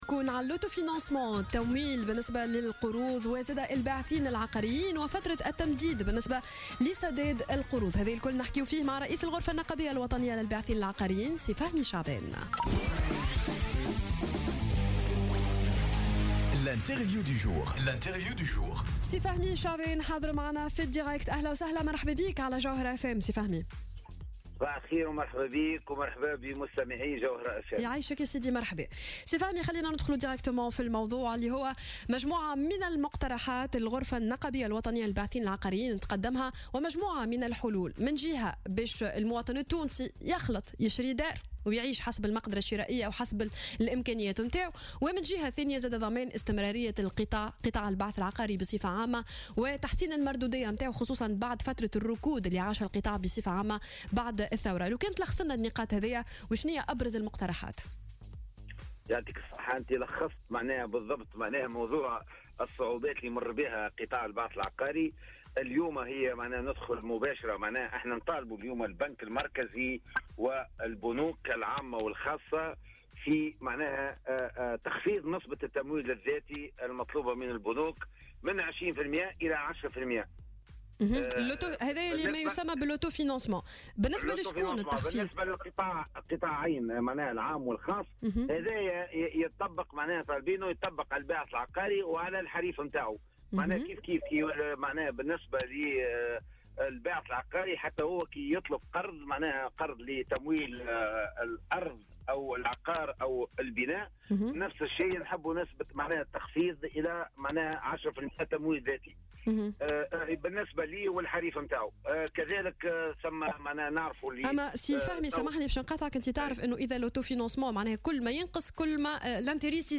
مداخلة له في صباح الورد على الجوهرة "اف ام" صباح اليوم